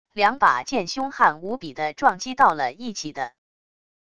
两把剑凶悍无比的撞击到了一起的wav音频